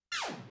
shooting.wav